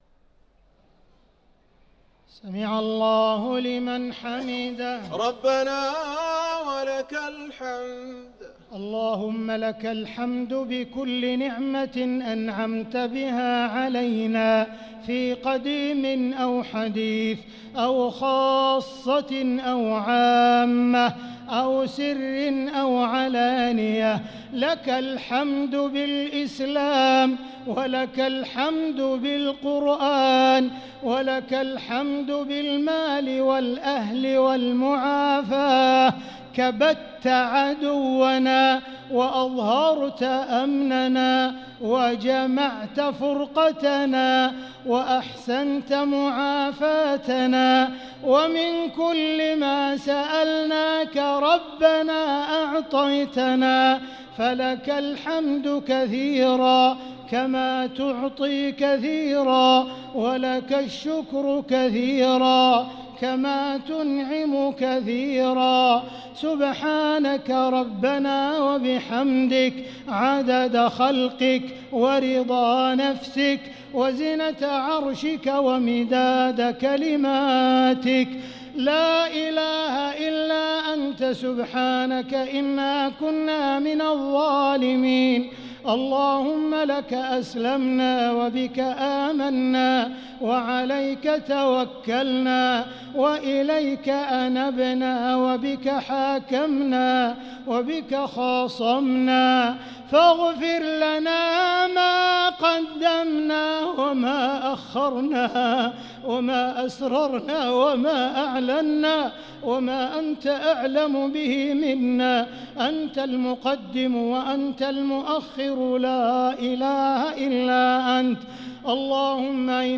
صلاة التراويح ليلة 19 رمضان 1445 للقارئ عبدالرحمن السديس - التسليمة الأخيرة صلاة التراويح